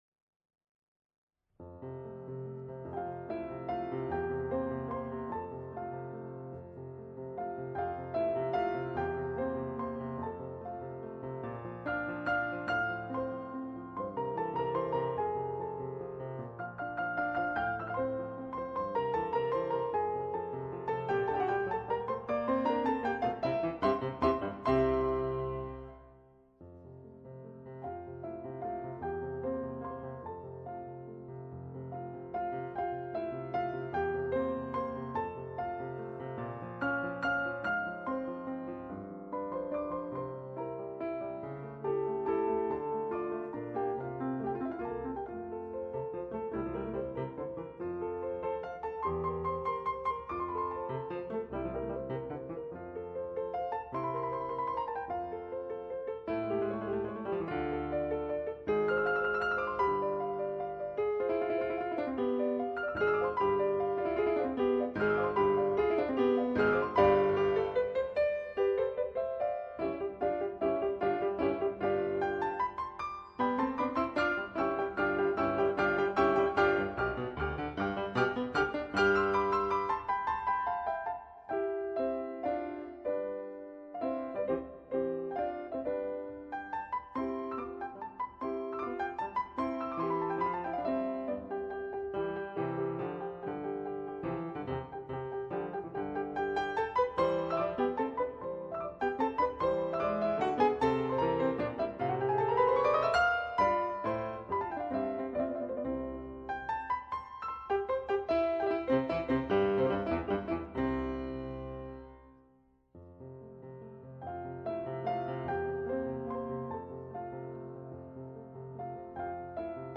Kuhlaus 4-hand music
piano